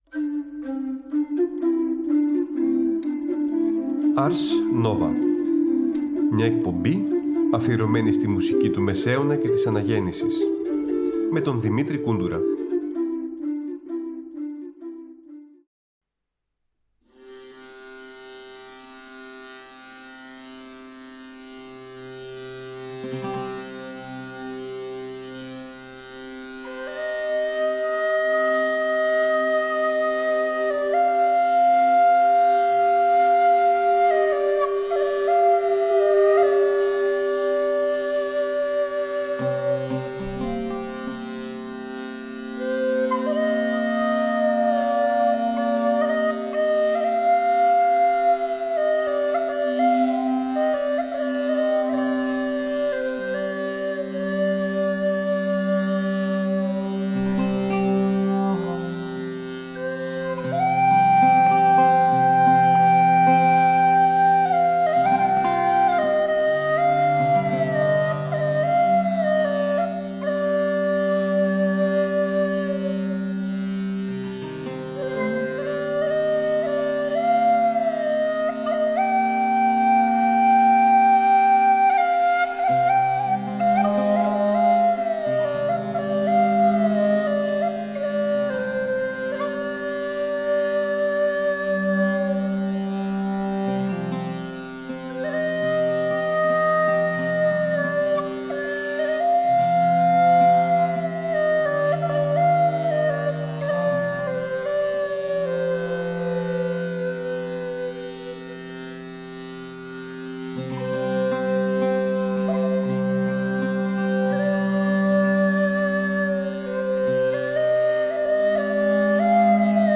Ωριαία μουσική εκπομπή του Τρίτου Προγράμματος που μεταδίδεται κάθε Τρίτη στις 19:00.